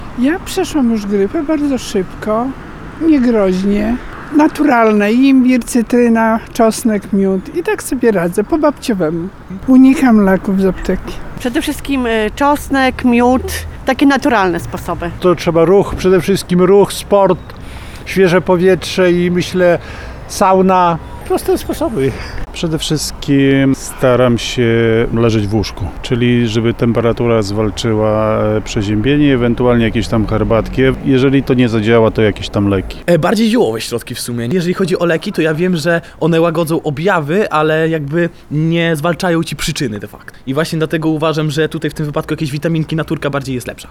We wtorek obchodzony jest Światowy Dzień Chorego, w związku z tym pytaliśmy przechodniów na ulicach Suwałk jak, radzą sobie z przeziębieniem i jakie sposoby na walkę z chorobą mogą polecić innym.